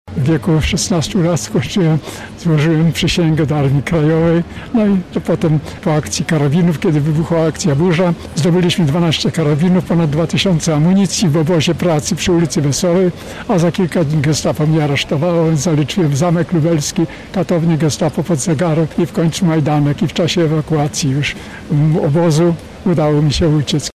Z tej okazji także w Lublinie weterani oraz władze miejskie uczciły pamięć wszystkich poległych.